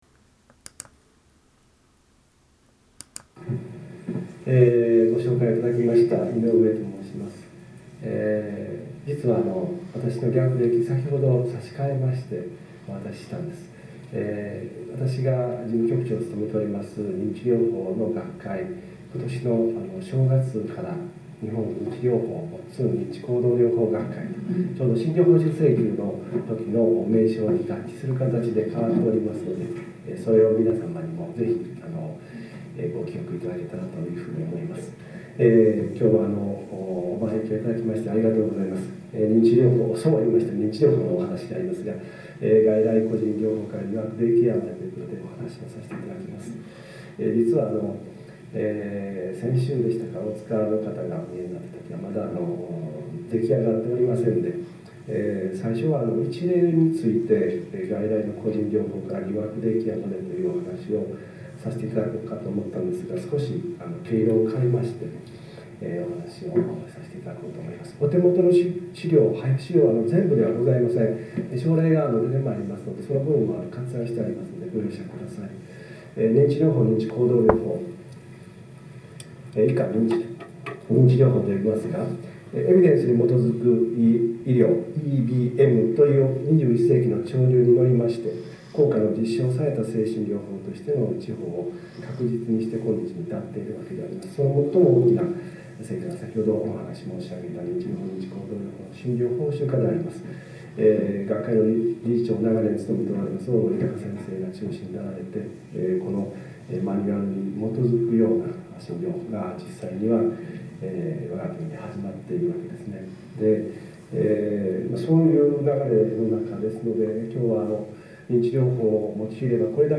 2016年9月10日例会（東京）における発表（音声）〕〔2016年9月10日例会（東京）における発表（スライド）